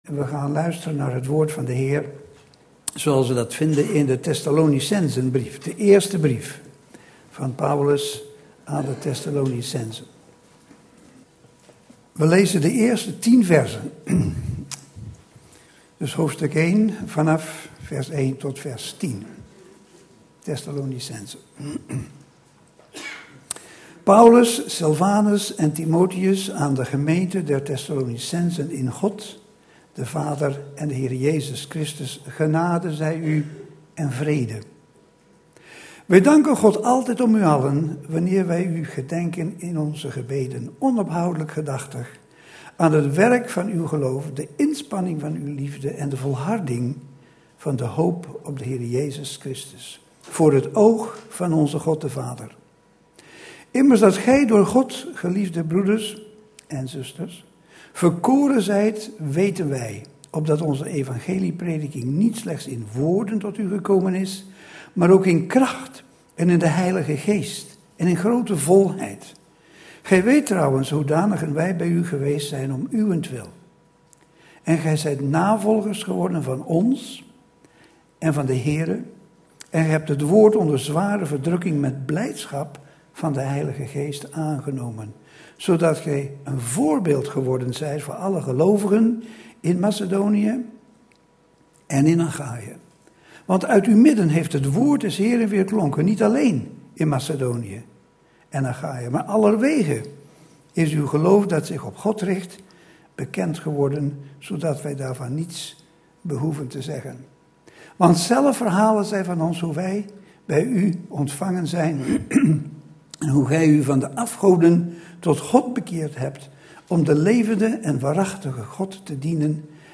In de preek aangehaalde bijbelteksten (Statenvertaling)1 Tessalonicenzen 1:1-101 Paulus, en Silvanus, en Timotheus, aan de Gemeente der Thessalonicensen, welke is in God den Vader, en den Heere Jezus Christus: genade zij u en vrede van God, onzen Vader, en den Heere Jezus Christus.